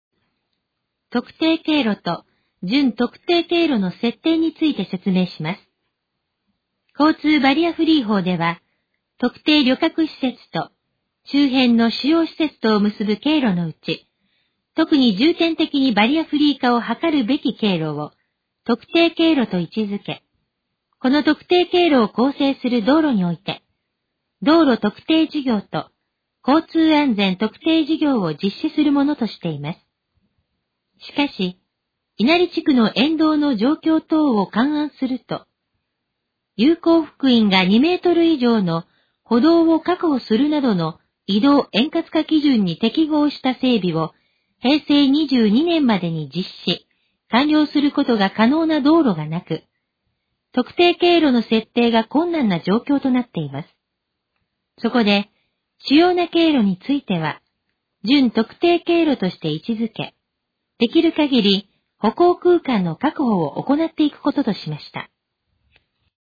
以下の項目の要約を音声で読み上げます。
ナレーション再生 約129KB